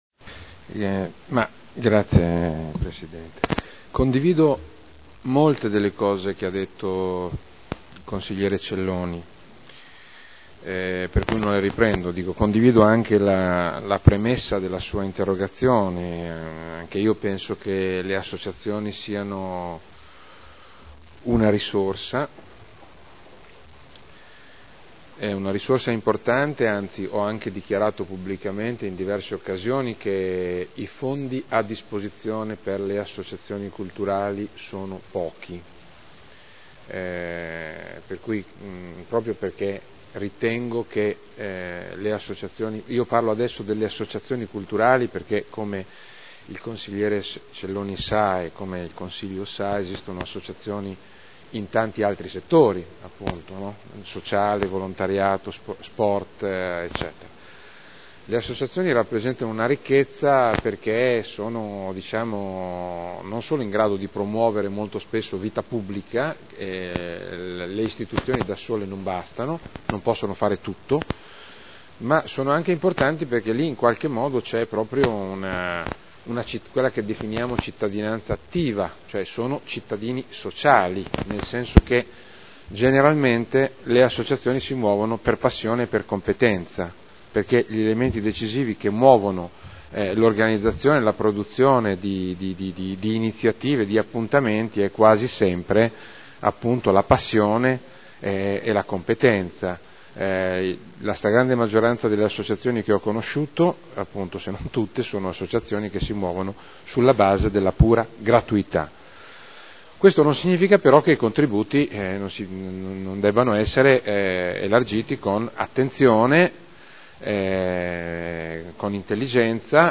Roberto Alperoli — Sito Audio Consiglio Comunale